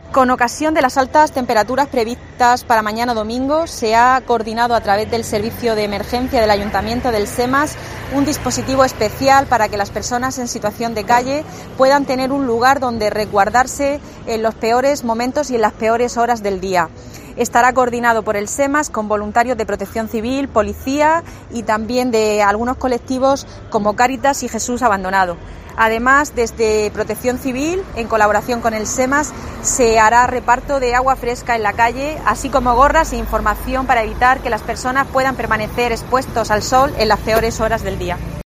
Paqui Pérez, portavoz del Ayuntamiento de Murcia